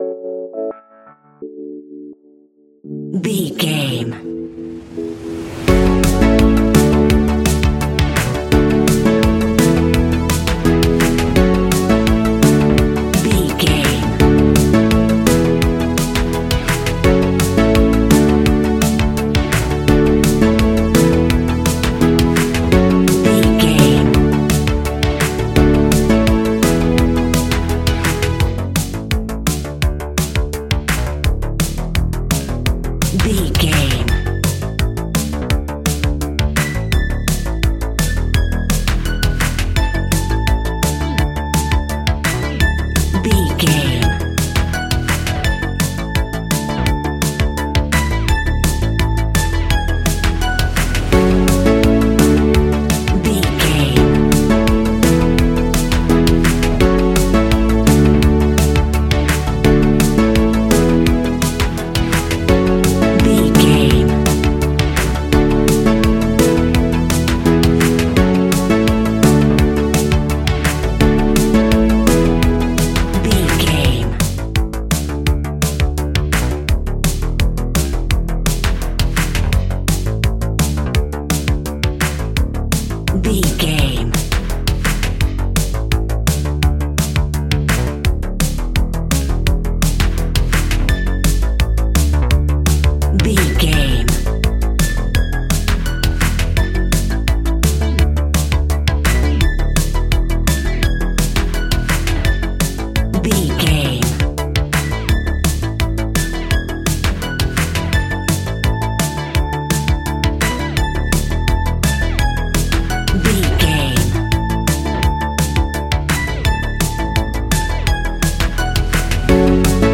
Ionian/Major
ambient
new age
chill out
downtempo
synth
pads
drone